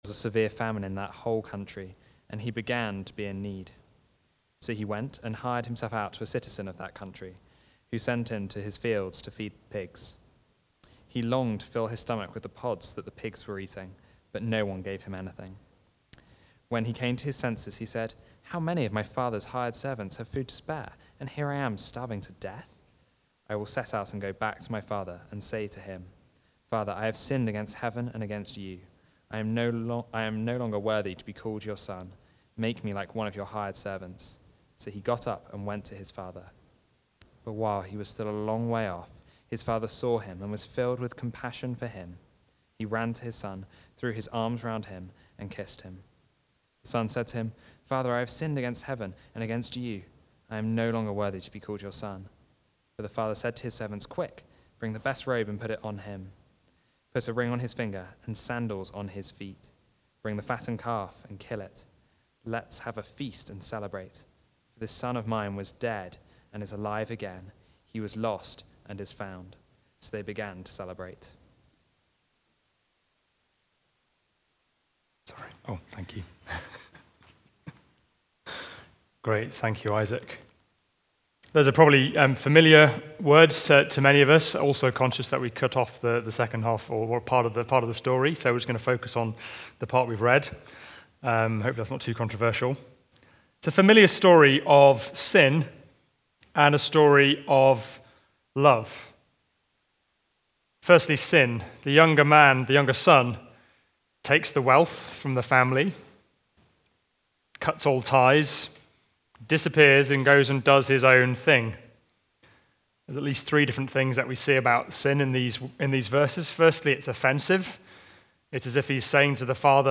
Preaching
Communion And Prayer (Luke 15:11) Recorded at Woodstock Road Baptist Church on 21 September 2025.